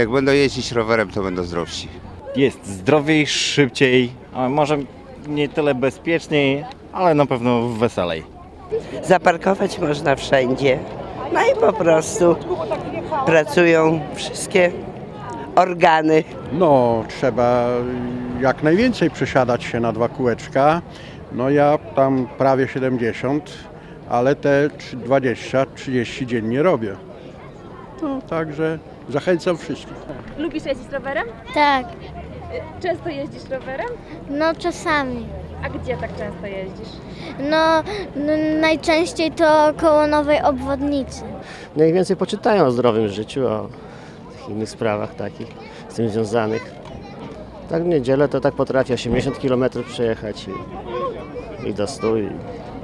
Jak przyznali w rozmowie z Radiem 5, aktywność fizyczna jest bardzo ważna, a ruch wpływa na dobre zdrowie.
rowerzyści.mp3